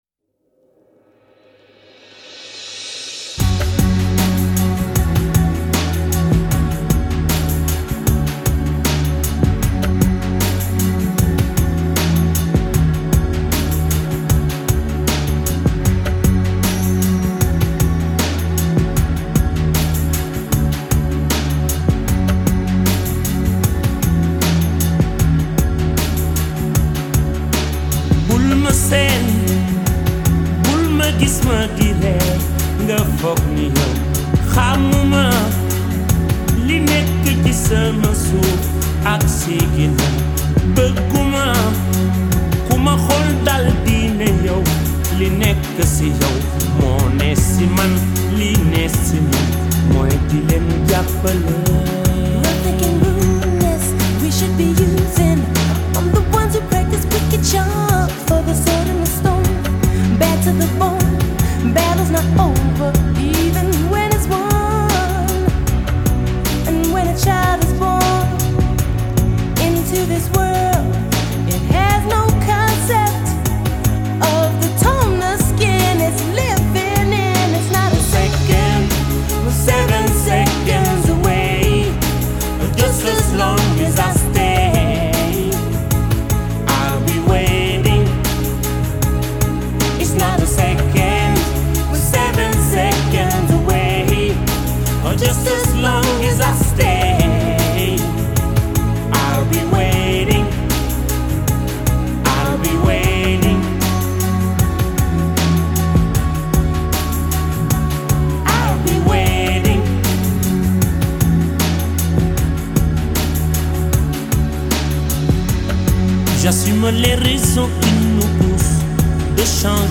7. Welke Senegalese zanger zingt